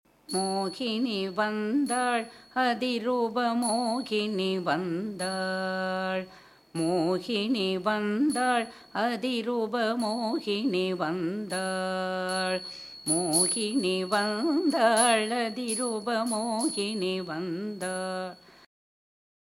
இராகம் : சௌராட்டிரம் தாளம் - மிச்ரம்